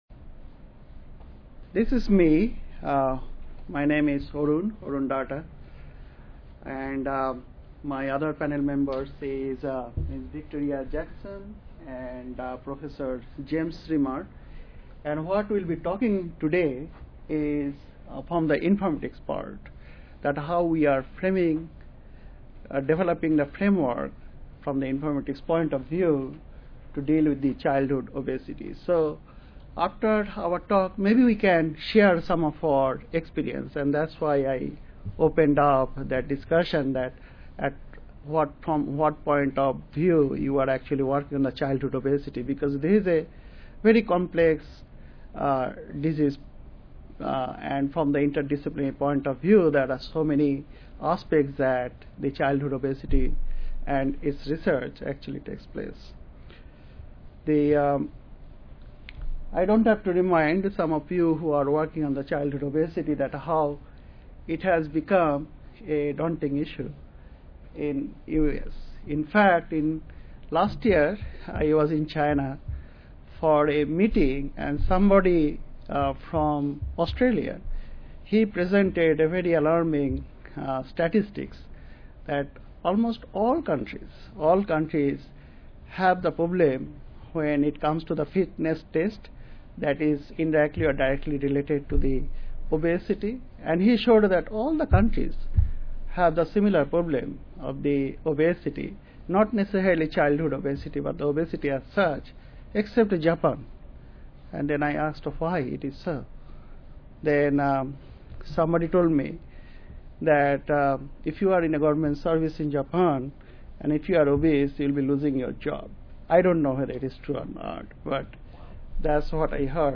This panel will discuss the opportunities and challenges of these ongoing developments in the context of PHIN�s strategic plan on standard-based public health information exchange (Draft Version 2.2.1, 3/17/11).
The proposed expert panel members will discuss the issues and challenges of these two public health informatics projects on reducing/preventing obesity in the context of PHIN's new strate